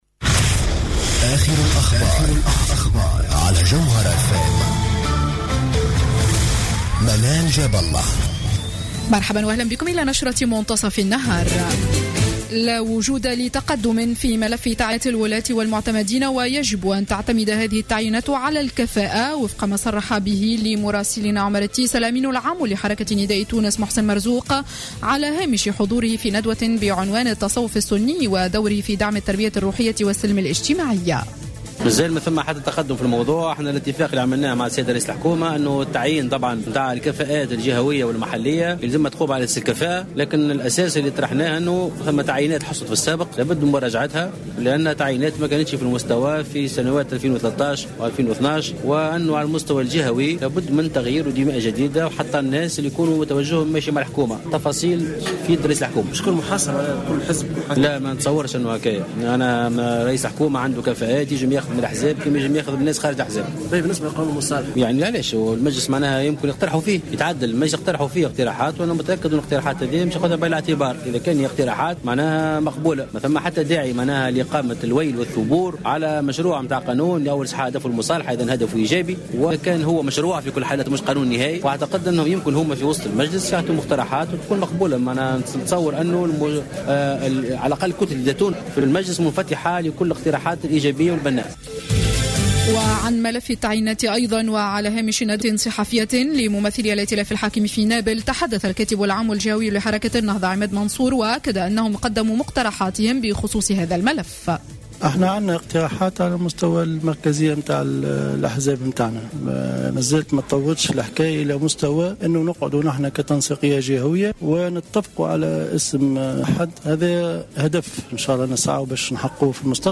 نشرة أخبار منتصف النهار ليوم السبت 08 أوت 2015